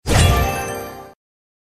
c_levelup.mp3